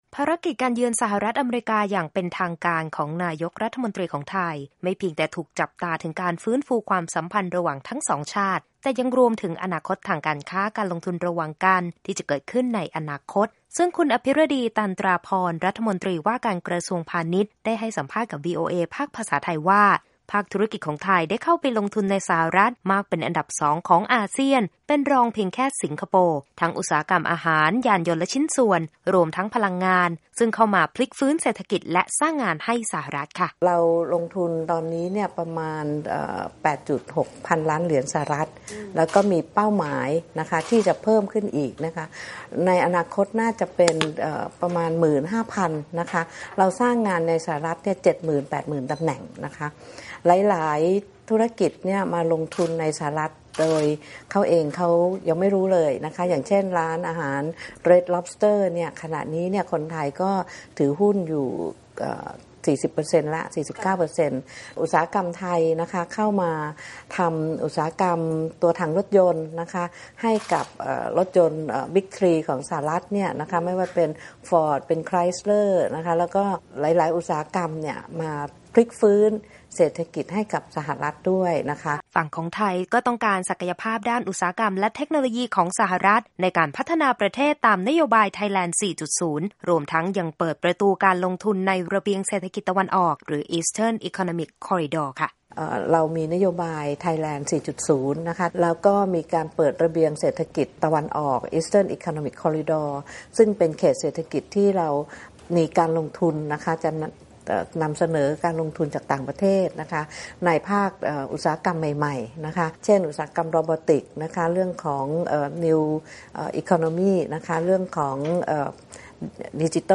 Thailand’s Minister of Commerce Apiradi Tantraporn talks with VOA Thai during her visits in Washington, DC. Oct 4, 2017.
Thai MOC Interview